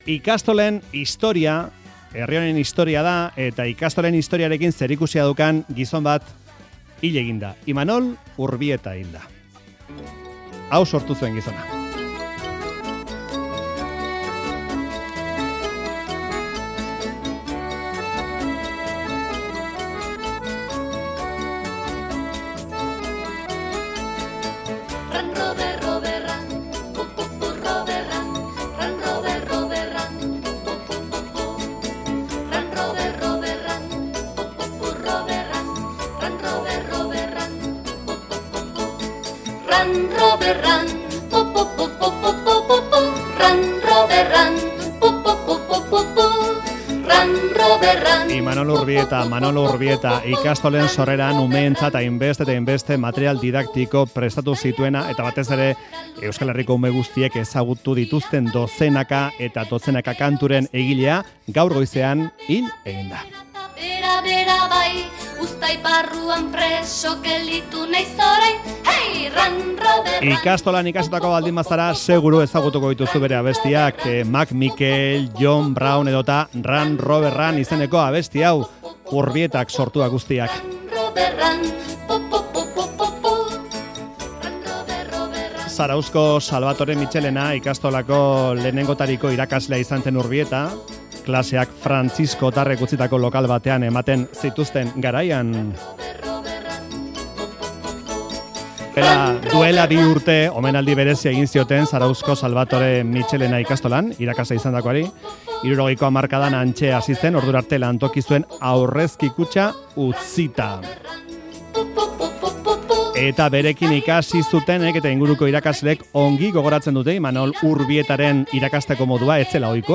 Audioa: Imanol Urbieta musikari, irakasle eta euskal haurren kantu ezagunenetakoen egilea hil da. Andoni Egañarekin hitz egin dugu.